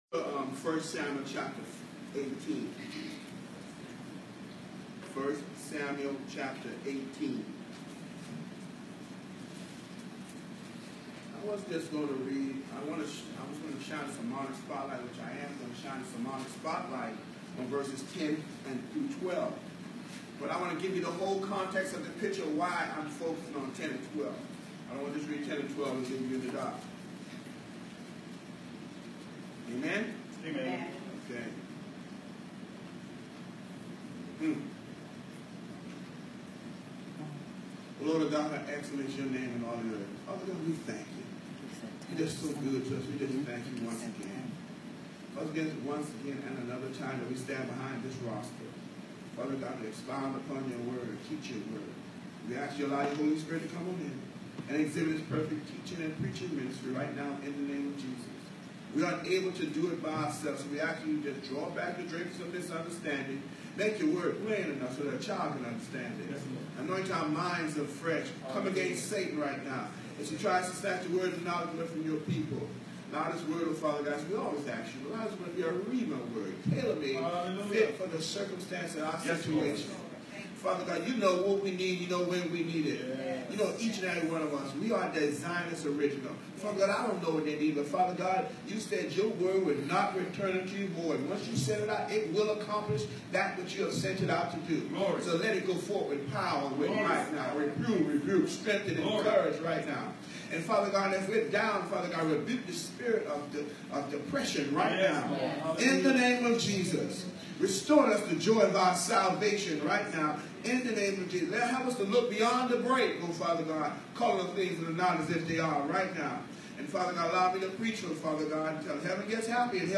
New Jerusalem MB Church July 15, 2018 Sunday 11:00am Worship Service 1 Samuel 18:1-13 The Message: The Cost Of Favor